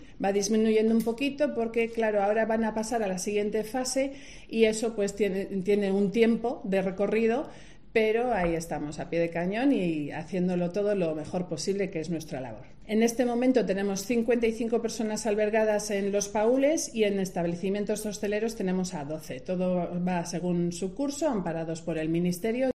Declaraciones
en la presentación del cupón del Sorteo del Oro que será el día 21 de julio y repartirá 7M€ en premios.